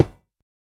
Minecraft Version Minecraft Version snapshot Latest Release | Latest Snapshot snapshot / assets / minecraft / sounds / block / iron / break6.ogg Compare With Compare With Latest Release | Latest Snapshot
break6.ogg